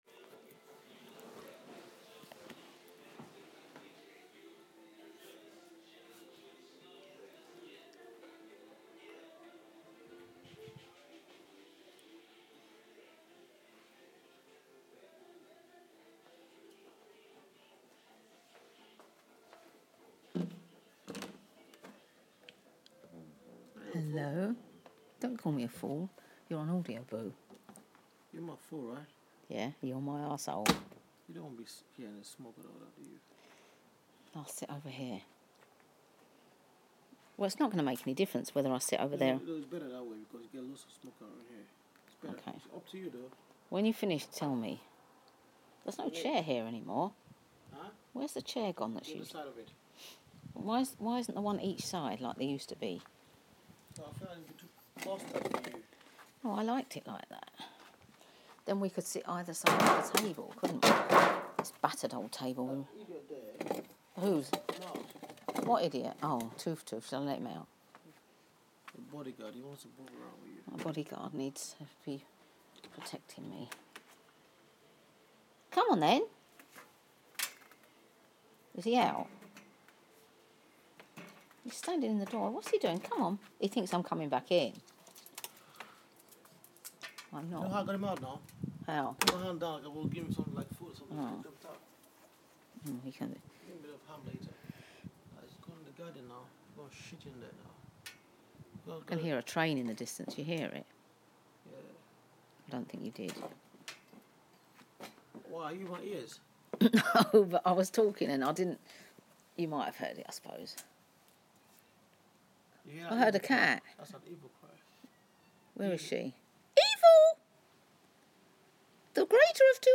in the garden late at night